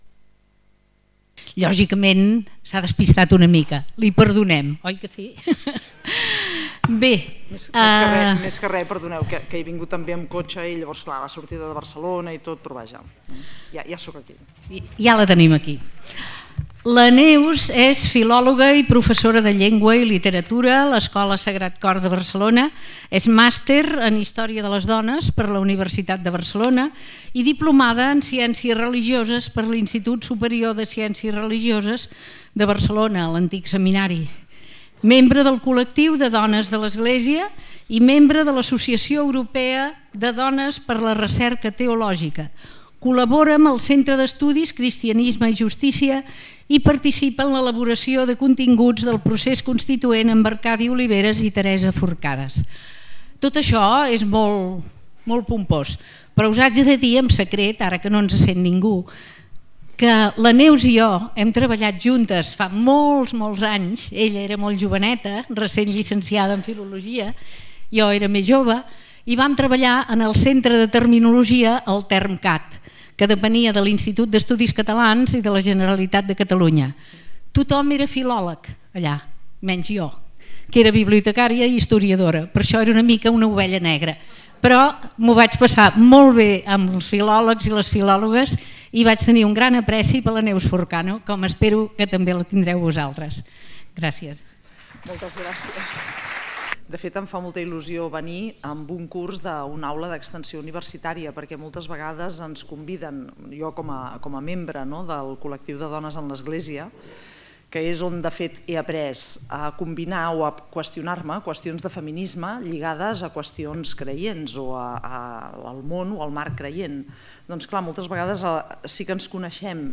Al final ha exposat un punt de reflexió d’una història de la Bíblia “Rut i Noemí” amb relació amb la situació de les dones. En conjunt, ha estat una xerrada amb un gran nombre de recomanacions i referències.